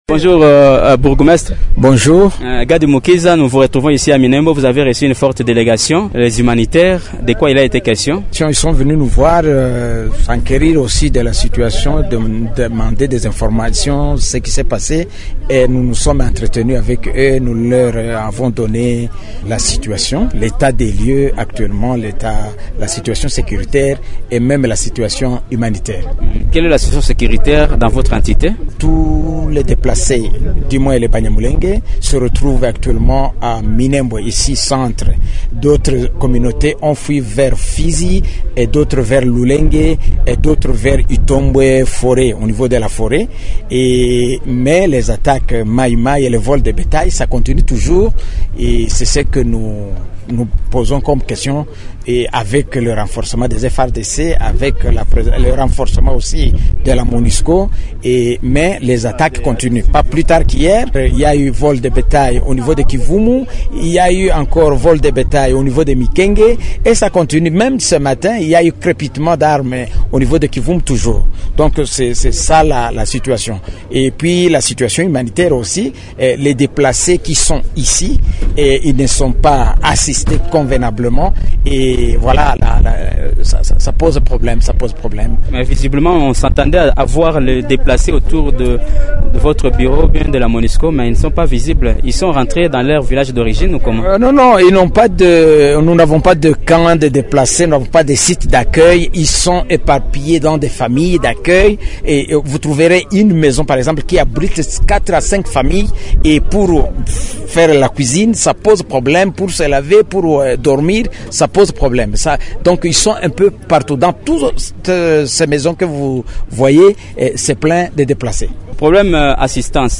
Sud-Kivu : « Nous demandons que les FARDC puissent s’impliquer davantage pour traquer les groupes armés » (Gady Mukiza, Bourgmestre de la commune rurale de Minembwe)